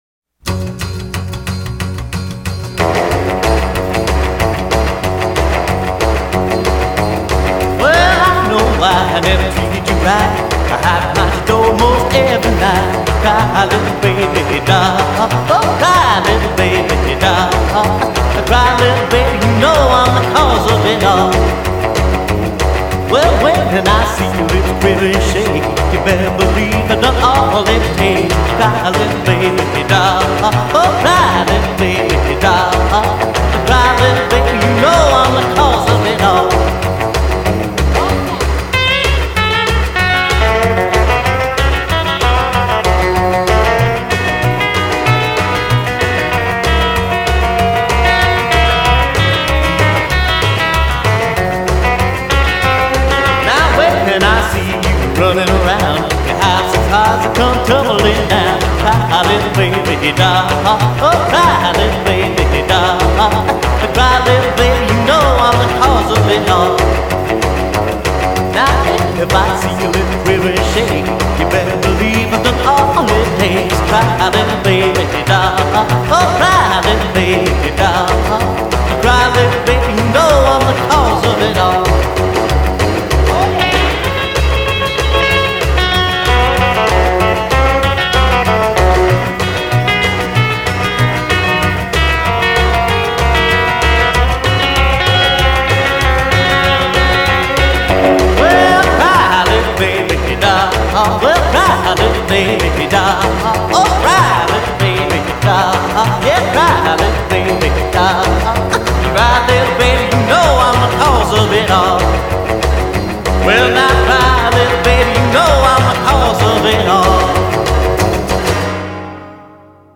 White-Hot Rockabilly